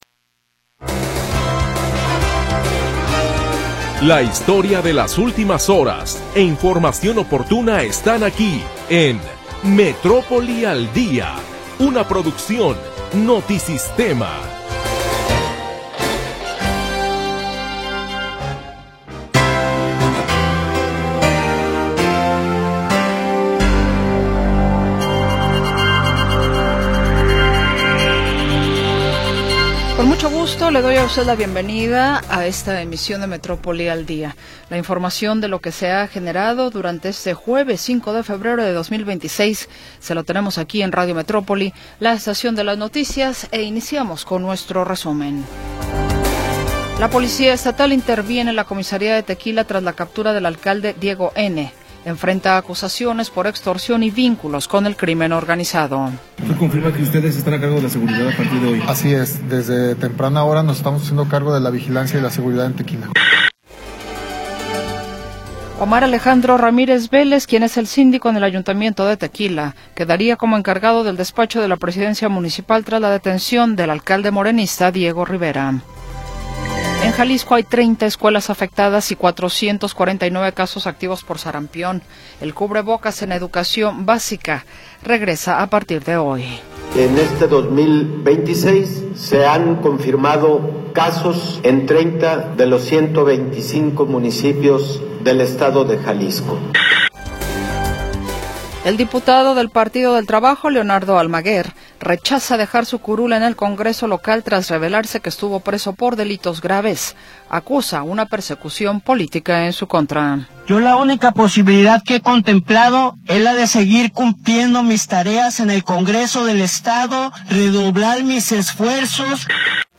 Primera hora del programa transmitido el 5 de Febrero de 2026.